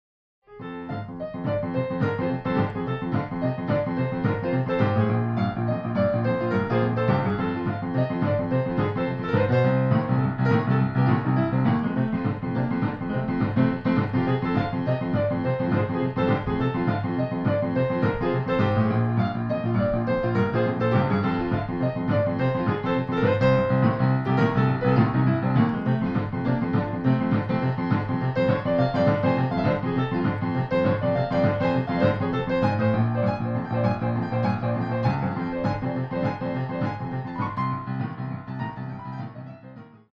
Style: Boogie Woogie Piano
Ein klassischer Boogie Woogie im Stil der 1940er Jahre.